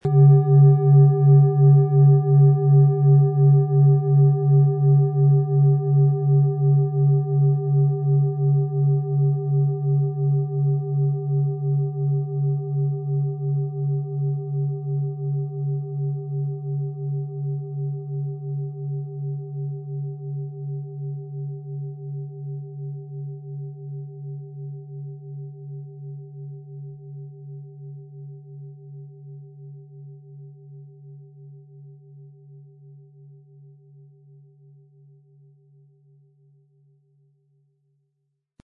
Planetenklangschale Pluto Frequenz: 140,25 Hz
Planetenklangschale: Pluto
Wochentag Tierkreiszeichen Element Pluto’s Farben Sonntag Skorpion Wasser Schwarz Blutrot Burgunder Weinrot Kastanienbraun Der Pluto-Ton Frequenz: 140,25 Hz Ton nahe: Cis/D Zu jeder Klangschale wird ein passender Klöppel mitgeliefert, der die Schale herrlich zum Schwingen bringt.